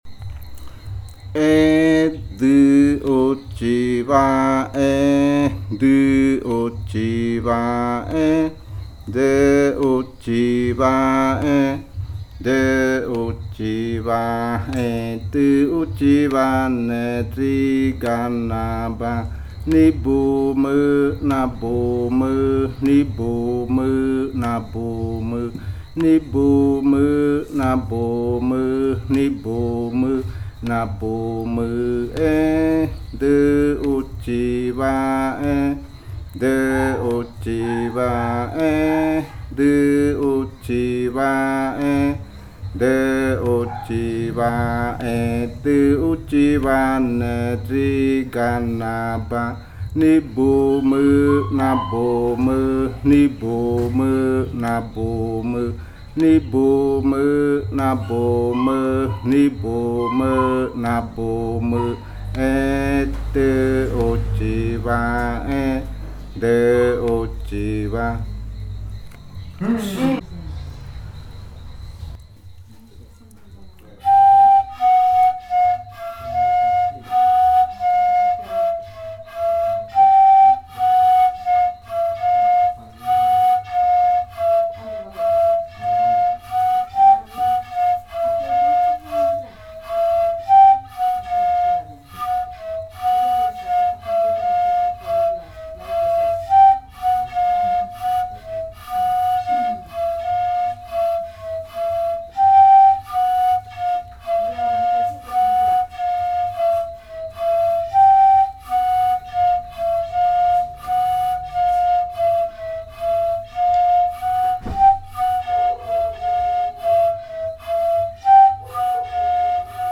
Grupo de danza Kaɨ Komuiya Uai
Canto Edɨ uchiba e interpretación del canto en pares de reribakui.
Edɨ uchiba chant and performance of the chant in reribakui flutes.
smaller, female flute
larger, male flute